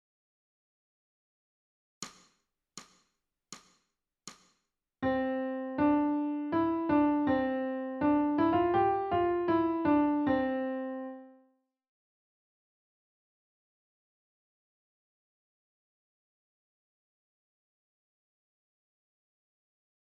ソルフェージュ 聴音: 1-iii-01